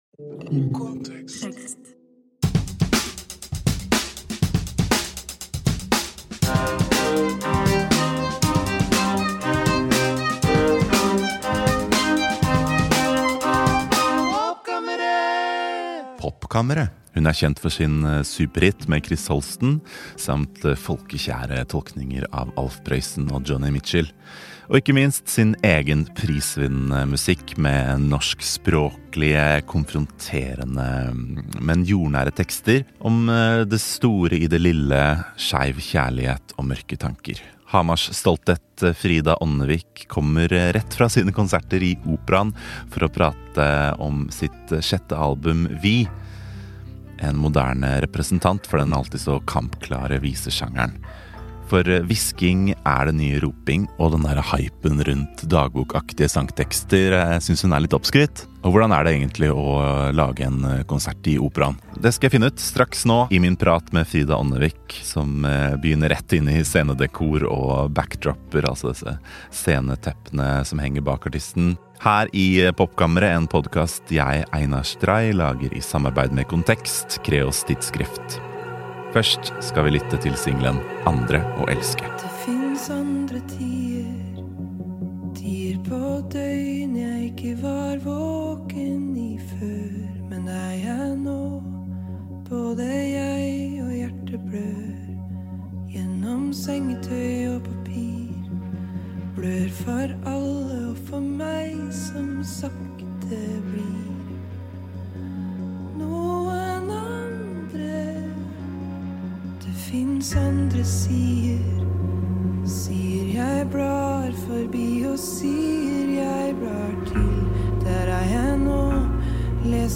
Hamars stolthet Frida Ånnevik kommer rett fra sine konserter i operaen for å prate med meg om sitt sjette album “VI”.